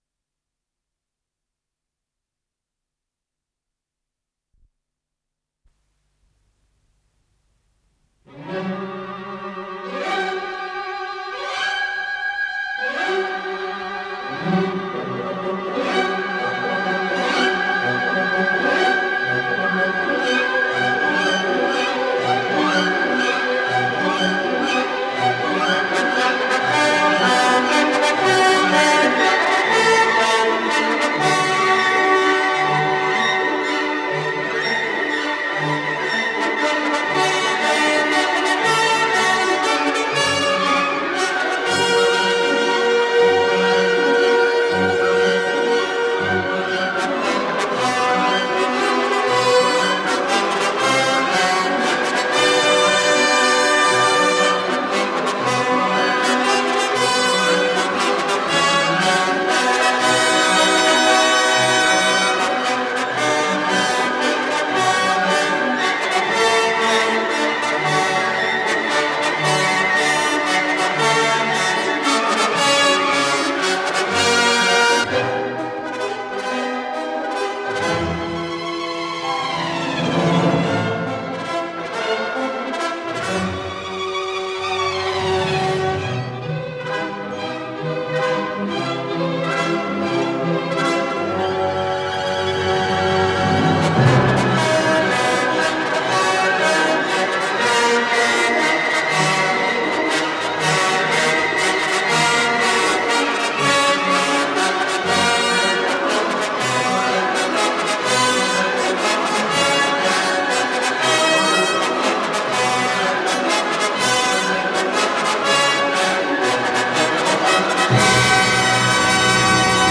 с профессиональной магнитной ленты
си минор
ВариантДубль моно